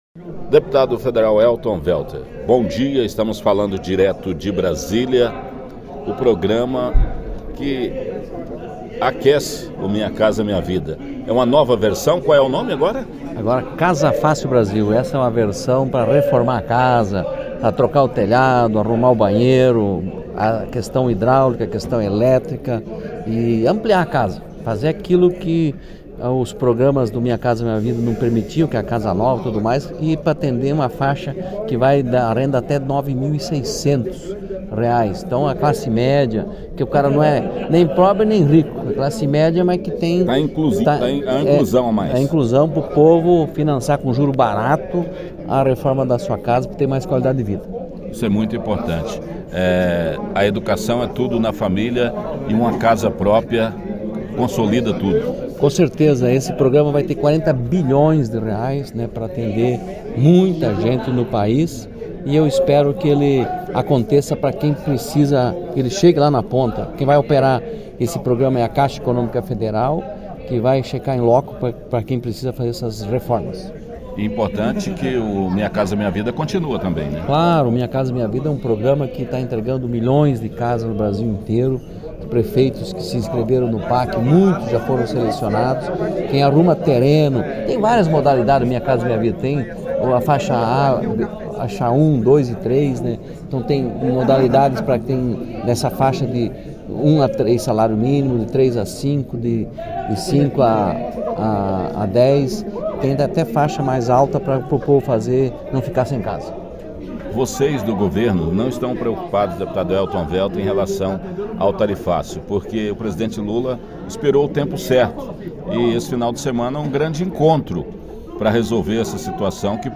Minha Casa, Minha Vida. O deputado Elton Welter (PT), da base do Governo Lula, comemorou a nova fase do programa de habitação lançado nos últimos dias. Ele conversou com o nosso correspondente em Brasília